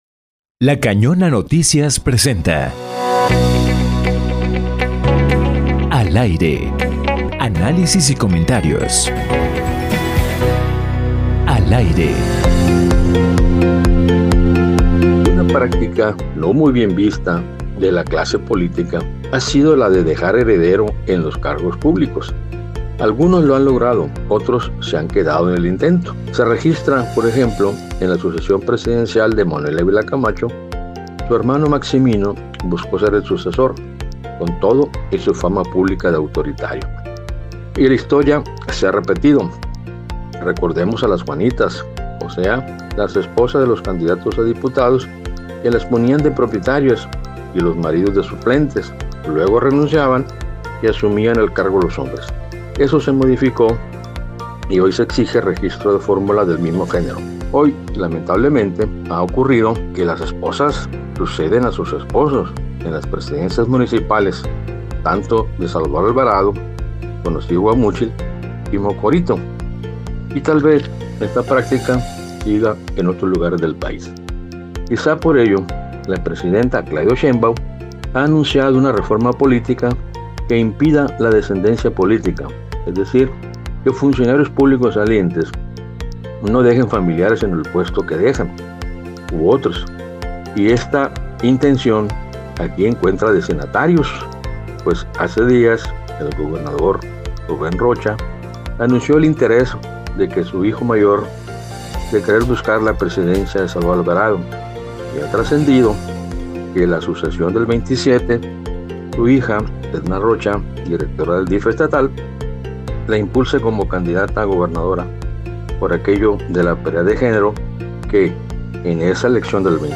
Analista político